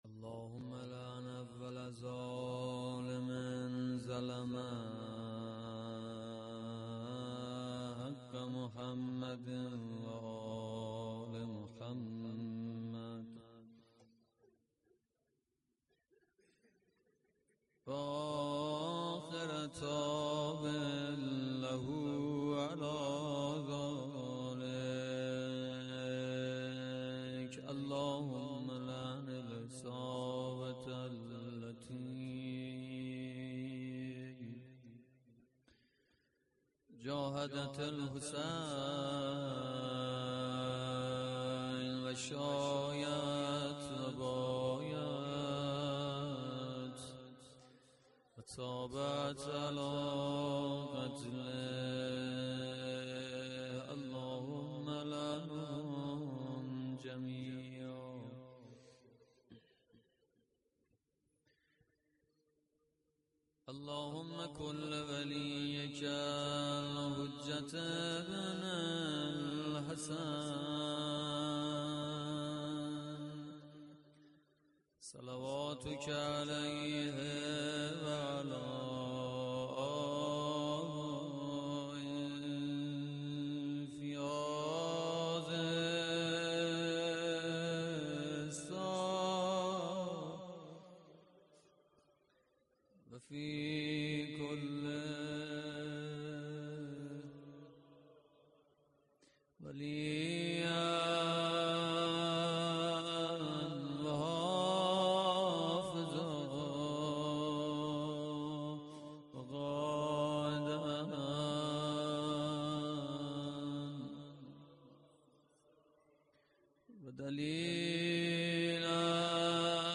مداحی روضه شب ششم